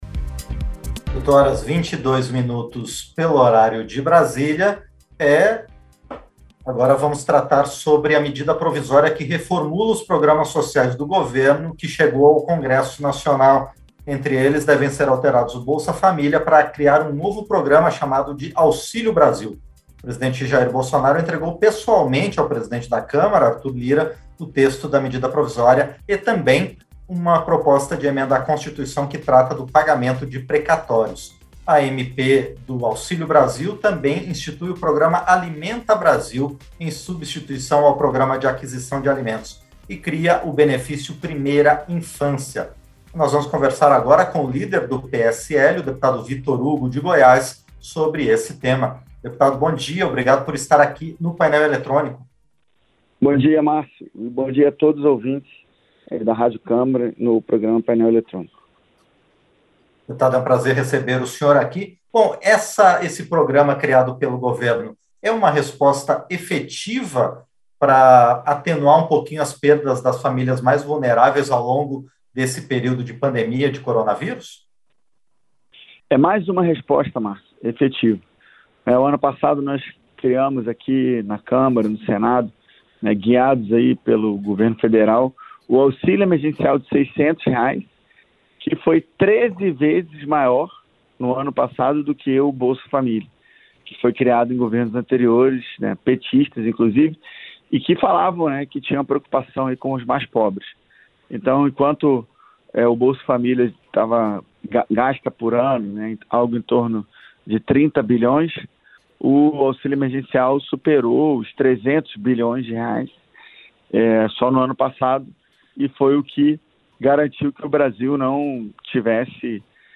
Entrevista - Dep. Vitor Hugo (PSL-GO)